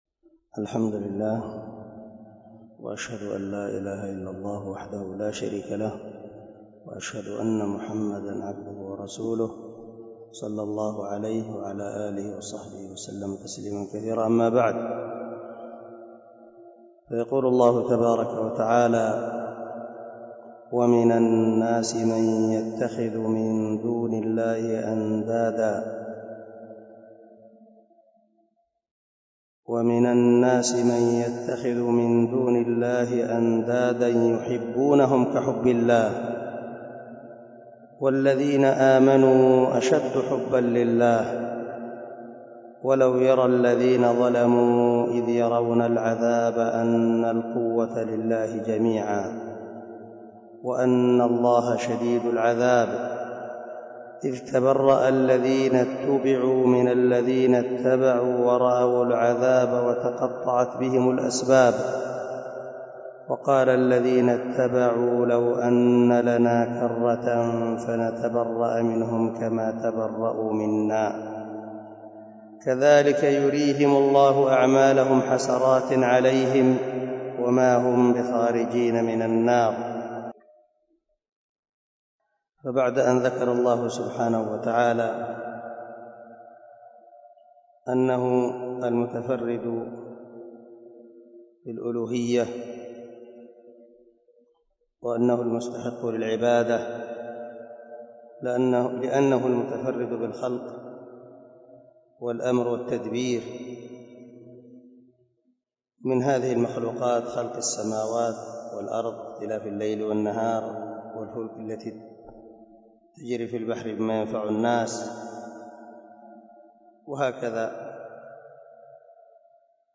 072الدرس 62 تفسير آية ( 165 – 167 ) من سورة البقرة من تفسير القران الكريم مع قراءة لتفسير السعدي
دار الحديث- المَحاوِلة- الصبيحة.